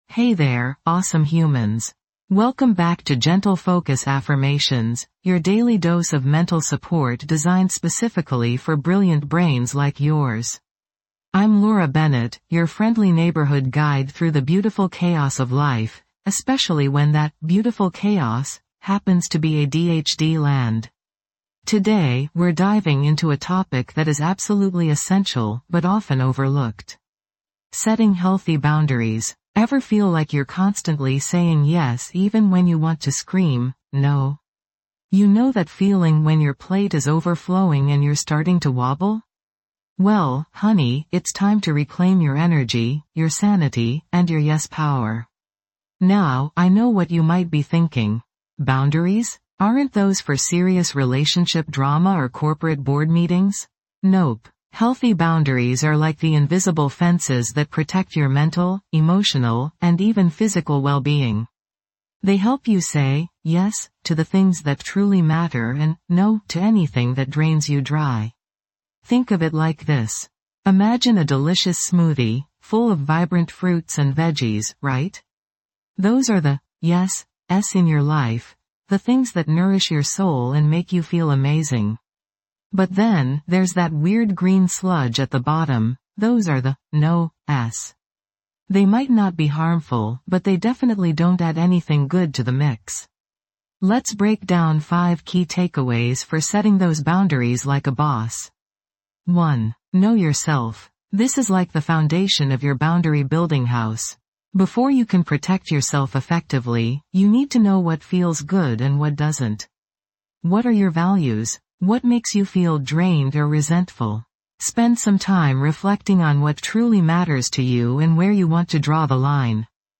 Each episode is carefully crafted with soothing affirmations designed specifically to support individuals with ADHD. These affirmations gently guide you towards focus, concentration, and emotional regulation, helping you navigate the challenges of daily life with greater ease and confidence. Whether you're struggling with inattention, hyperactivity, or executive function difficulties, our calming voice and empowering words will help you cultivate a sense of calm and mental strength.